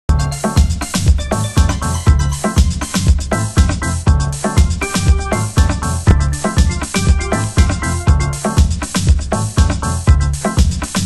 有/少しチリパチノイズ有